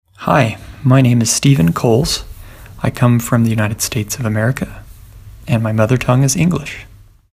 For all those who do not read IPA transcriptions fluently, there are some audio recordings: Whenever you see a ? symbol next to a name, you can click on it to listen to the speaker’s own pronunciation of their name. Most speakers say something along the lines of ‘Hi, my name is […], I come from […] and my mother tongue is […]’ – all that in (one of) their native language(s).